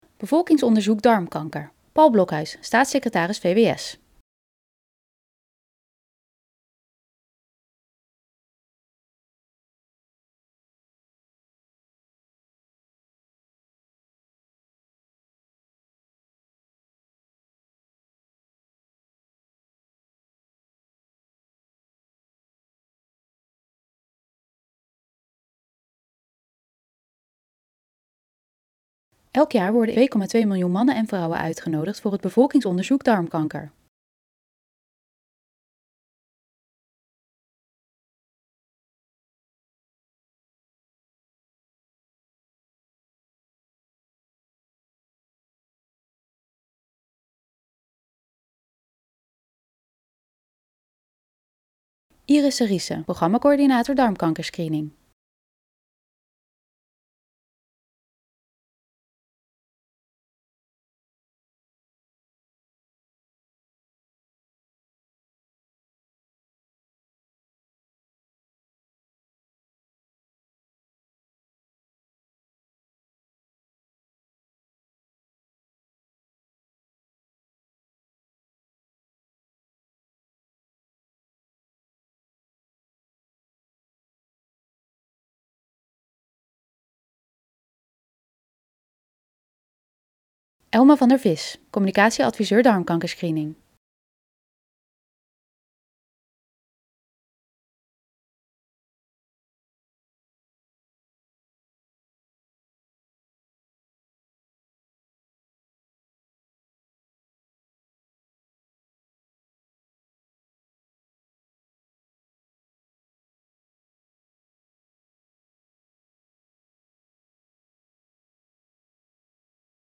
Paul Blokhuis (staatssecretaris van het ministerie van Volksgezondheid, Welzijn en Sport) introduceert de video door te vertellen over het Bevolkingsonderzoek Darmkanker.